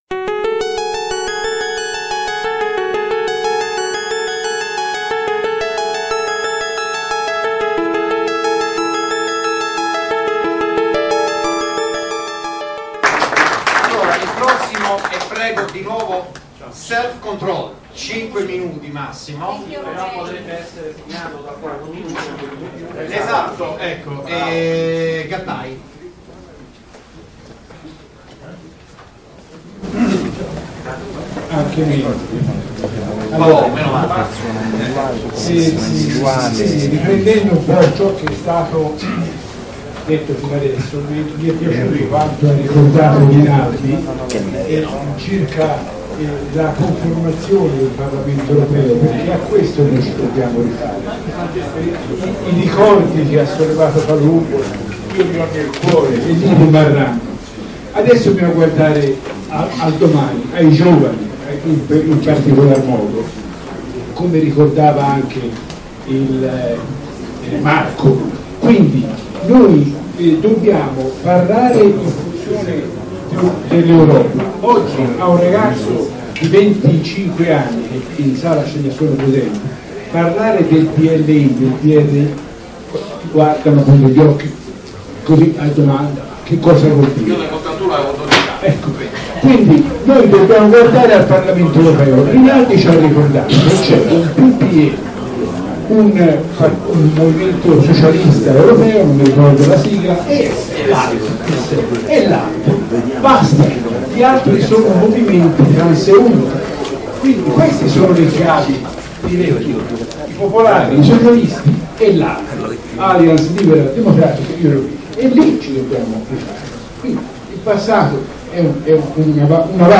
Assemblea Membri Individuali ALDE Italia, momento di incontro per gli iscritti e i simpatizzanti ALDE IM. Roma, 16 gennaio 2016,  presso la sede della LIDU Onlus - Sede nazionale Lega Italiana dei Diritti dell'Uomo.